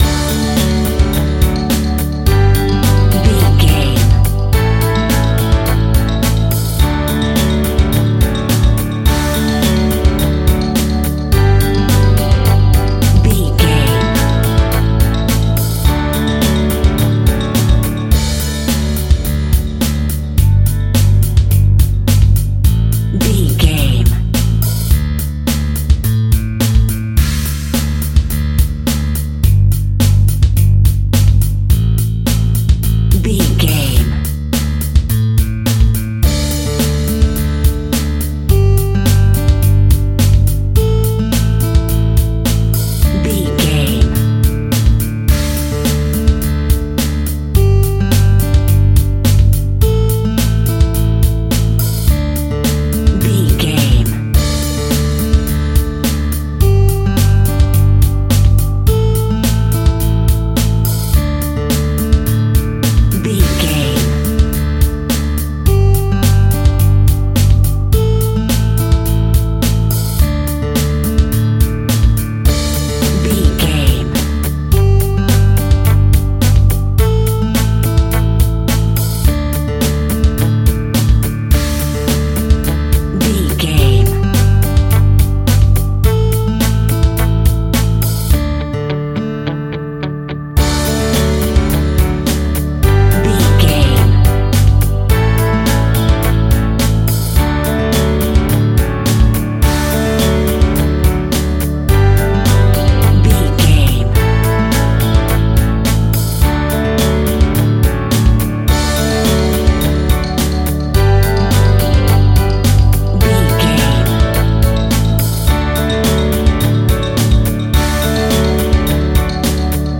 Upbeat Groove Music.
Ionian/Major
pop rock
fun
energetic
uplifting
synths
drums
bass
guitar
piano